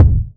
• Urban Kick Single Hit G Key 461.wav
Royality free kick drum tuned to the G note. Loudest frequency: 114Hz
urban-kick-single-hit-g-key-461-WKX.wav